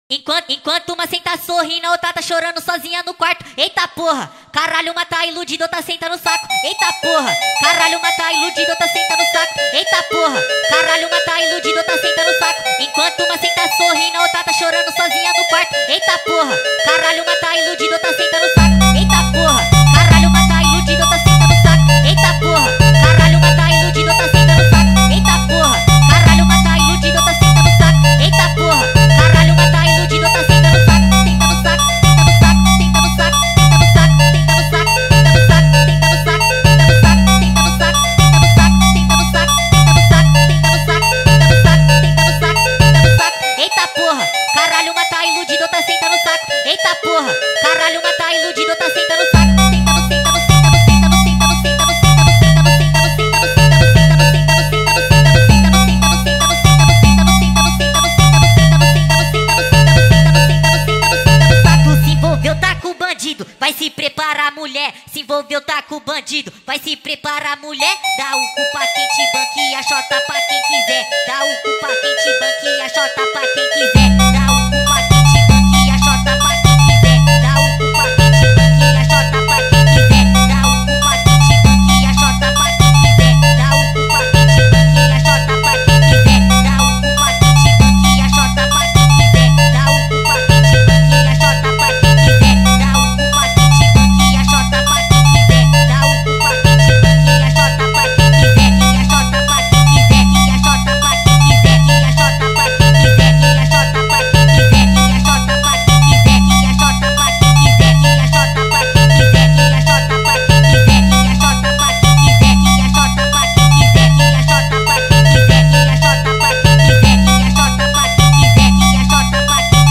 این فانک در نسخه Sped Up می باشد و ریتمی تند و خاص داره
فانک